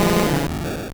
Cri de Magicarpe dans Pokémon Or et Argent.